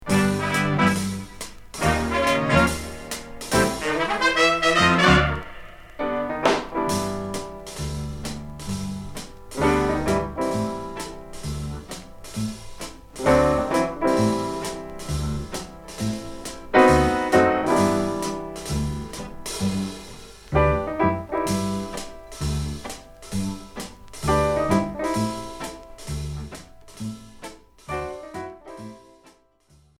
orgue éléctronique, son orchestre